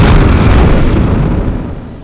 XPLODE2.WAV